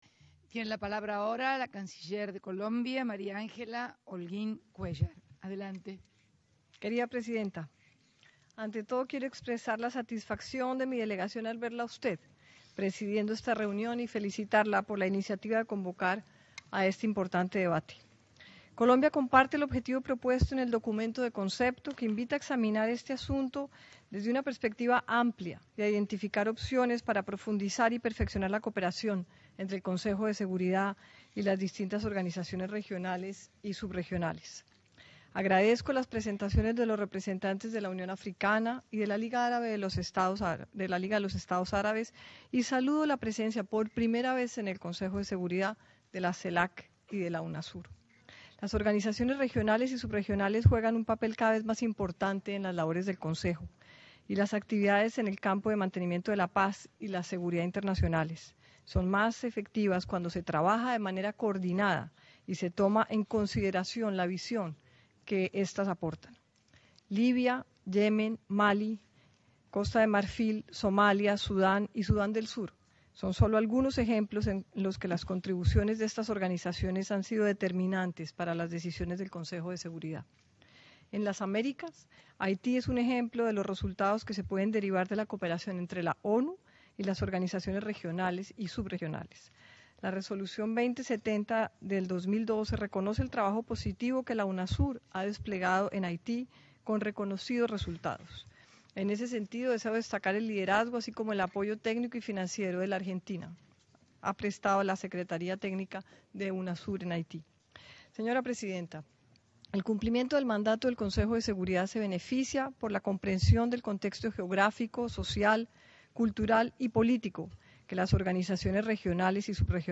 intervencion_de_la_canciller_en_el_consejo_de_seguirdad_onu.mp3